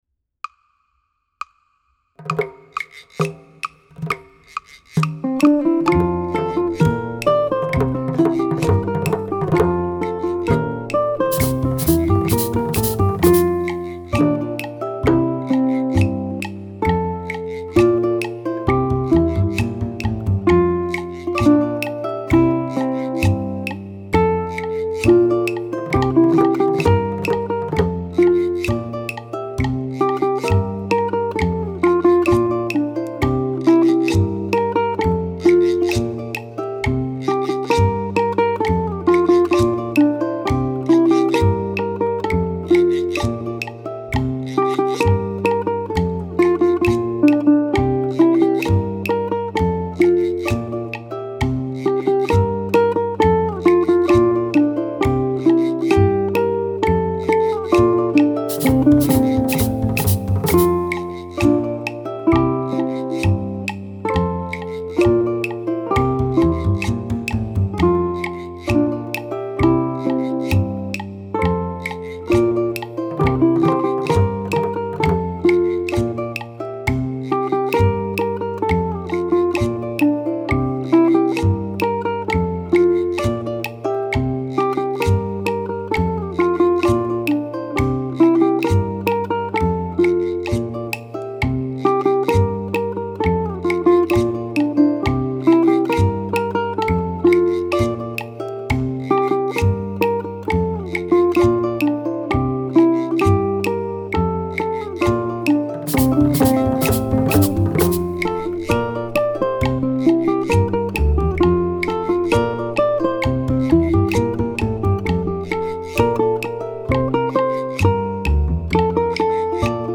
It only has three chords but the melody darts about the fingerboard and can dog the best of us with its rhythm and passage work.
It is an example of classic son jarocho musical style.
La Bamba should be performed with a lively tempo.
For chords, the flashy Wall Strum 4/4 is suggested.
ʻukulele